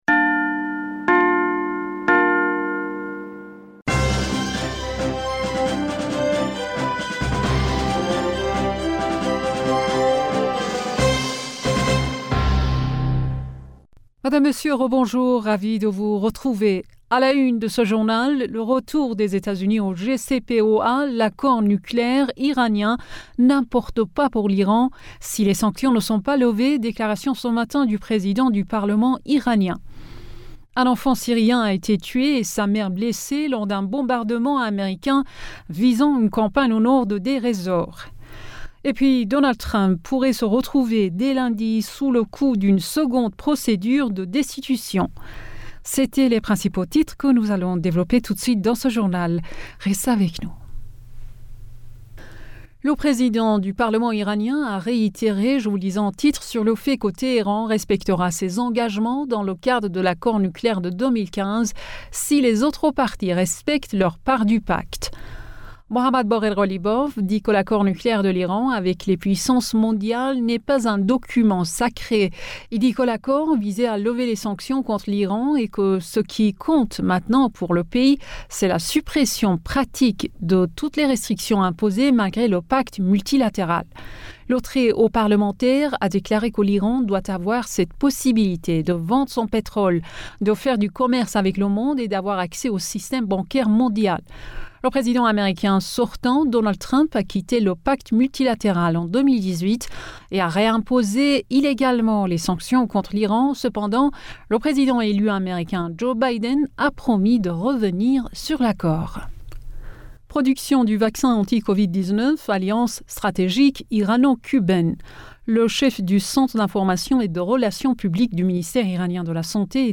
Bulletin d'informationd u 10 Janvier 2021